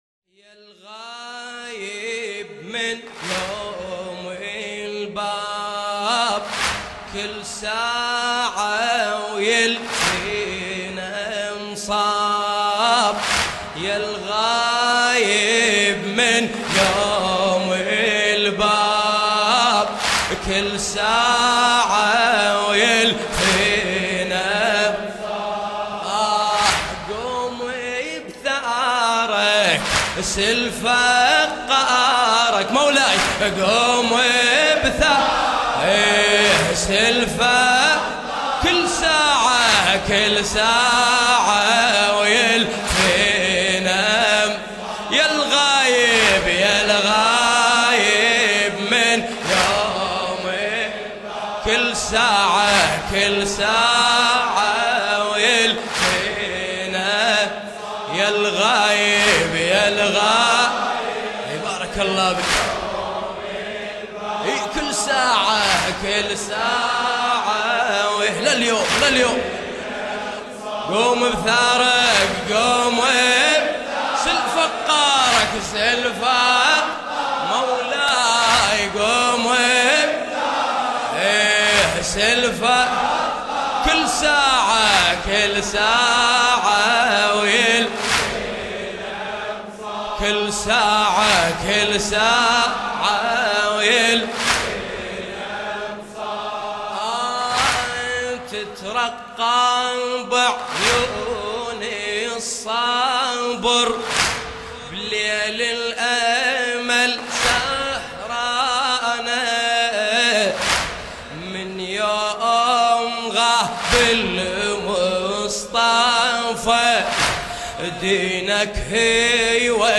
مداحی عربی
سینه زنی عراقی مداحی عراقی